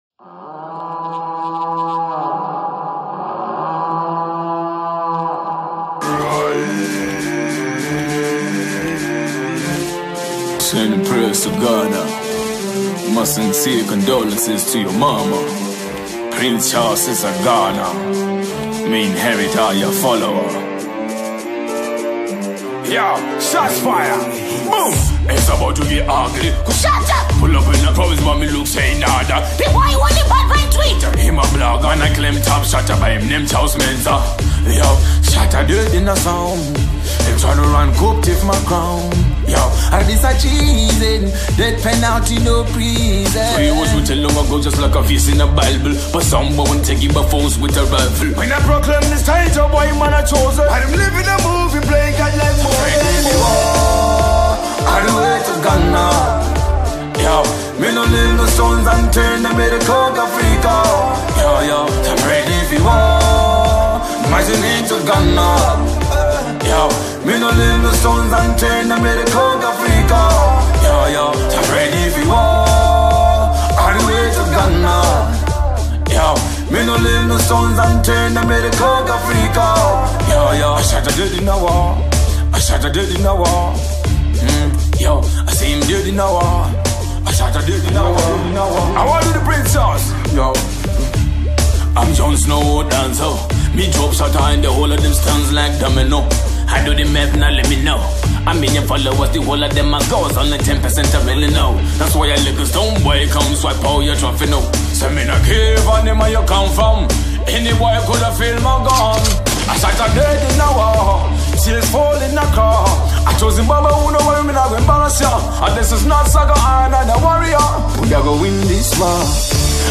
Zimbabwe dancehall artist